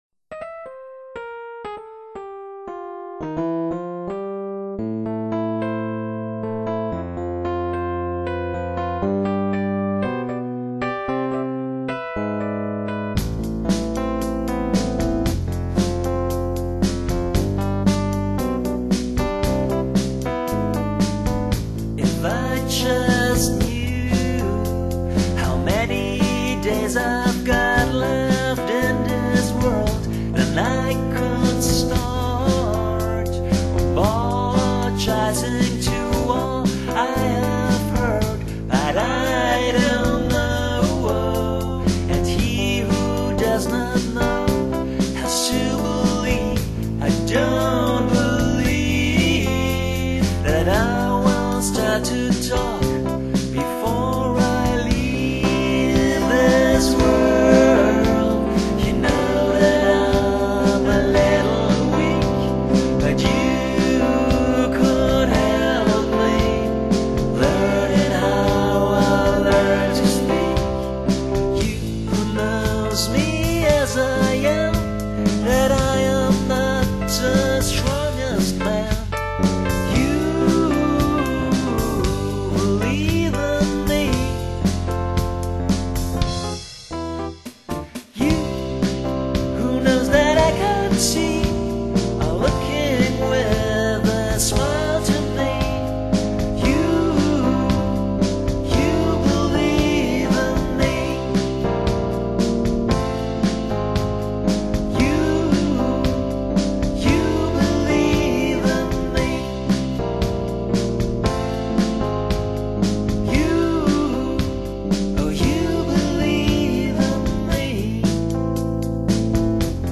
vocals, e-piano, drum arrangement